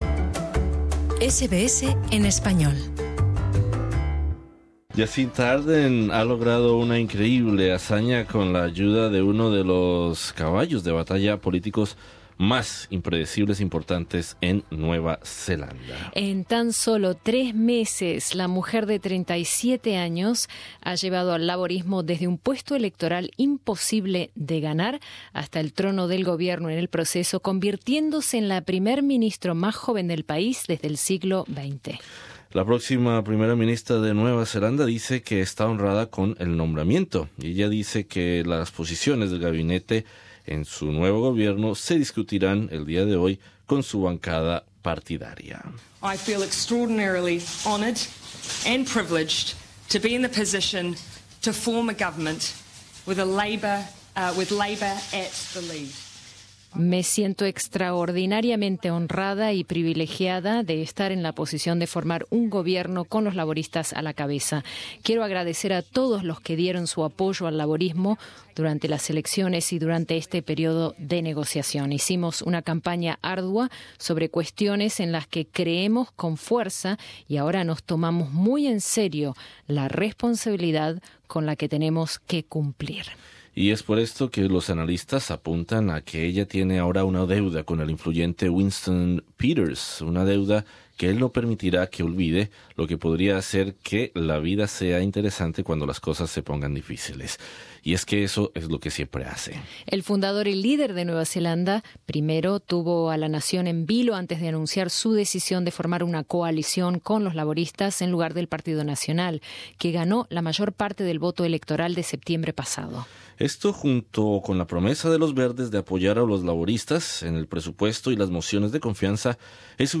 Entrevista con el diputado laborista del parlamento de Victoria, Telmo Languiller.